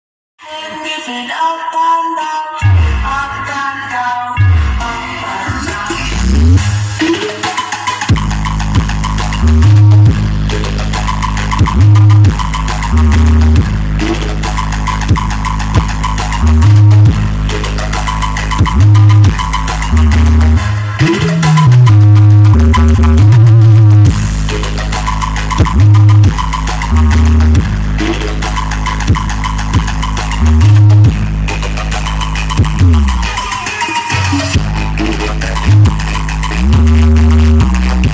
cek sound 6 sub spek sound effects free download
hajatan lokasi Sukomulyo Pajarakan